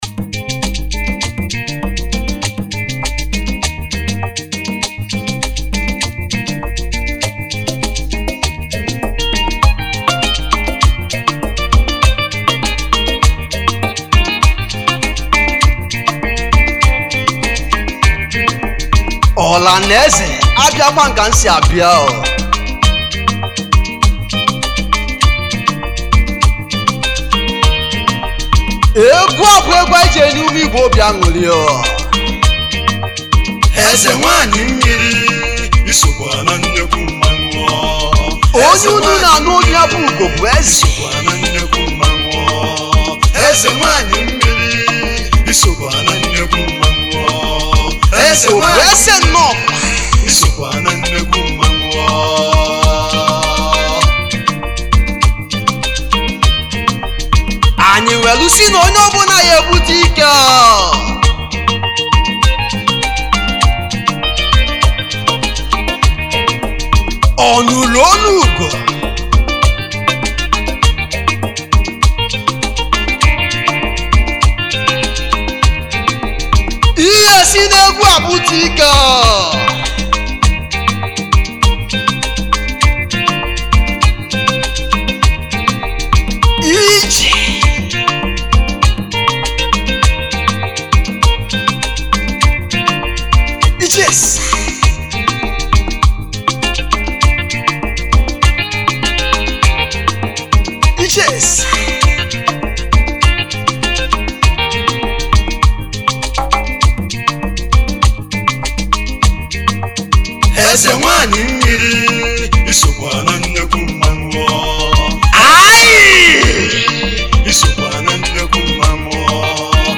Highlife Traditional Free